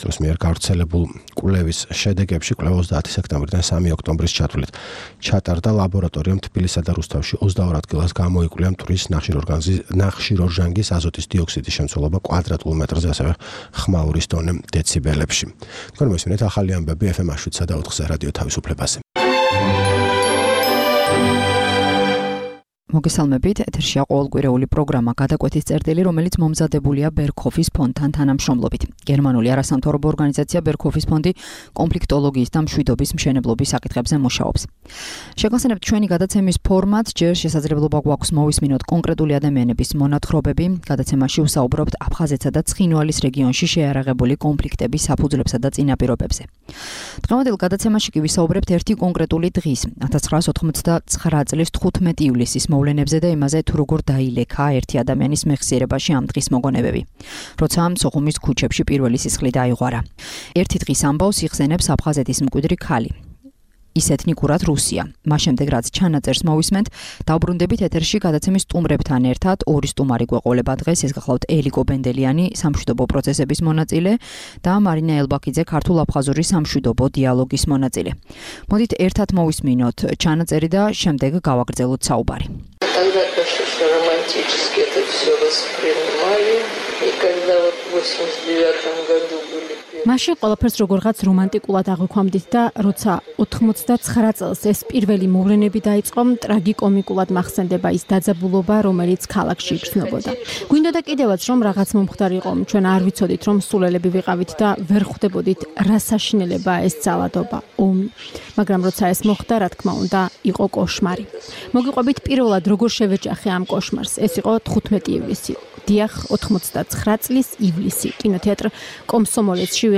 გადაცემაში ვსაუბრობთ ერთი კონკრეტული დღის, 1989 წლის 15 ივლისის, მოვლენებზე და იმაზე, თუ როგორ დაილექა ერთი ადამიანის მეხსიერებაში ამ დღის მოვლენები, როცა სოხუმის ქუჩებში პირველი სისხლი დაიღვარა. ერთი დღის ამბავს იხსენებს აფხაზეთის მკვიდრი ქალი.